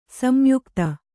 ♪ samyukta